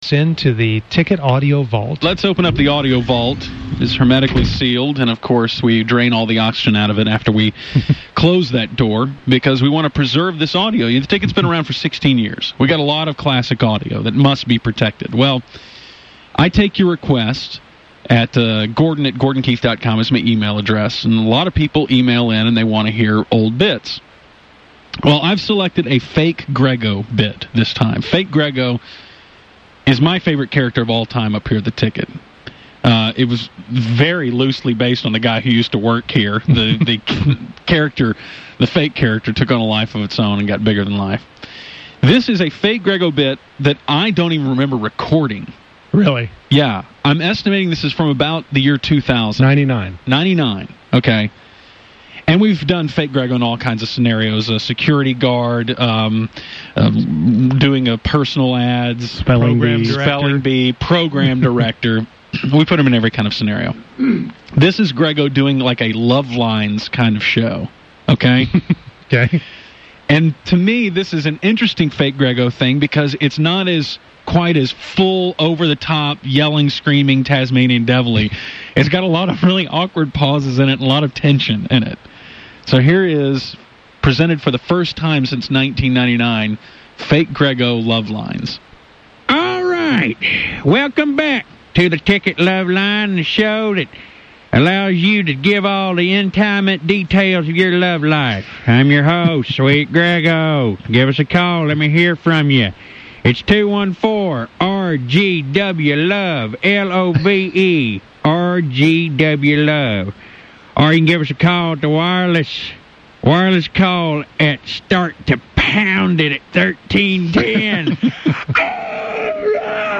weight talk, screaming, girls, dead air and his lisp. https